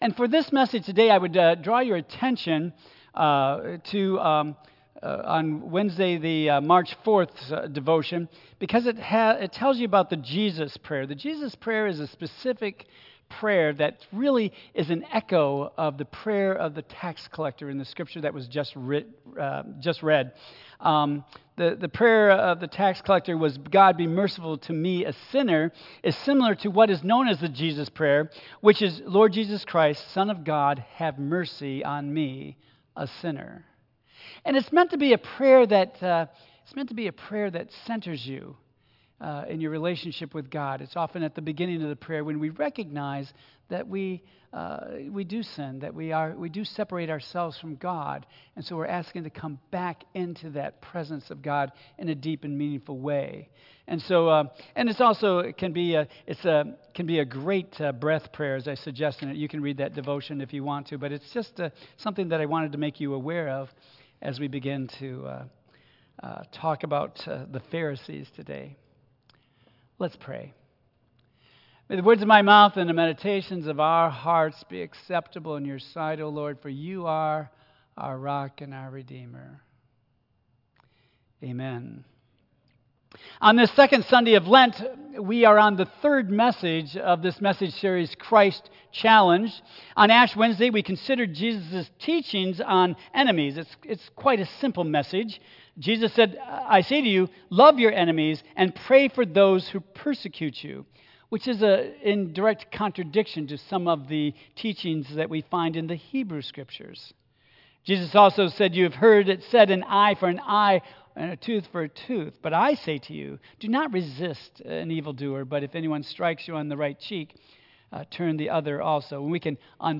Tagged with Michigan , Sermon , Waterford Central United Methodist Church , Worship Audio (MP3) 8 MB Previous The Trial of Temptation Next The Superficial Scribes